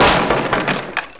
woodbreak.wav